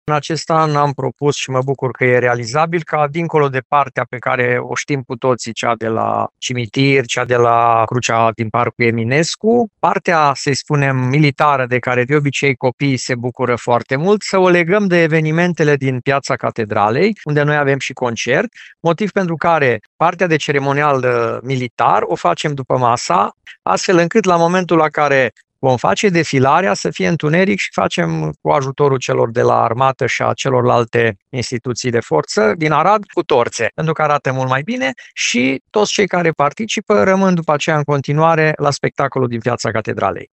Primarul municipiului Arad, Călin Bibarț, explică motivul pentru care întreg ceremonialul militar a fost mutat în a doua parte a zilei.